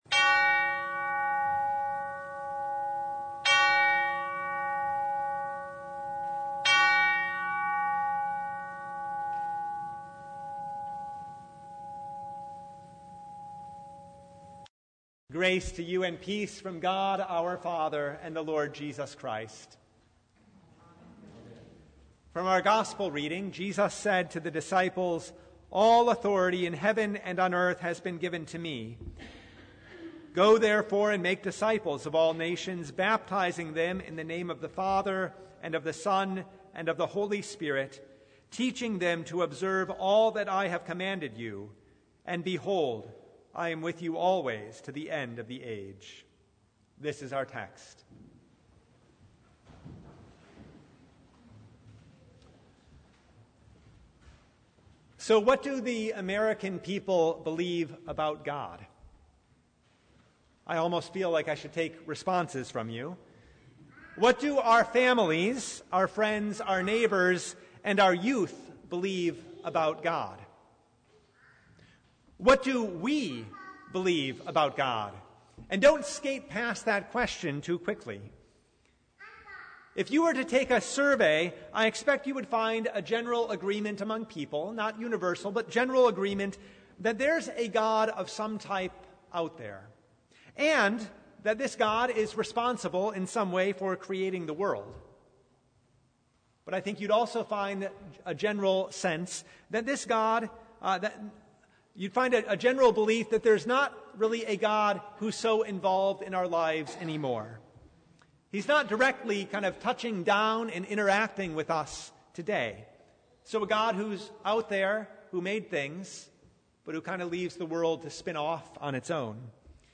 Service Type: The Feast of the Holy Trinity
Sermon Only